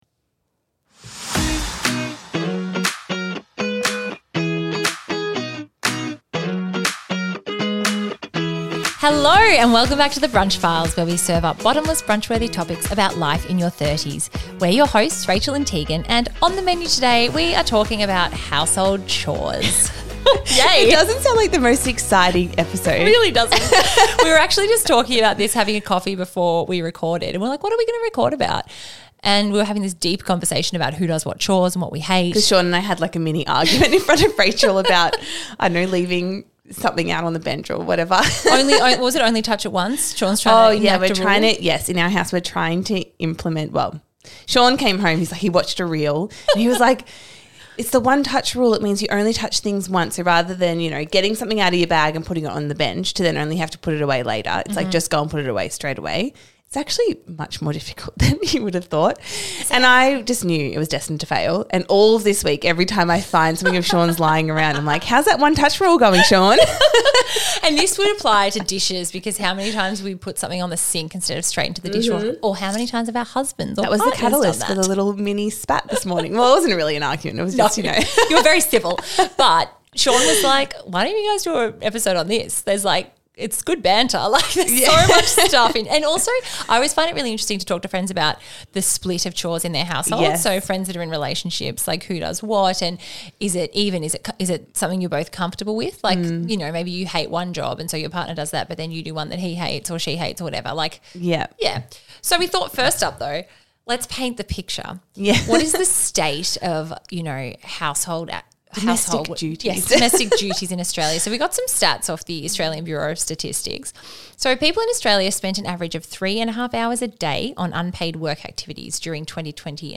We laughed so much recording this one and we promise you’ll enjoy it to!